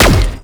Zapper_1p_01.wav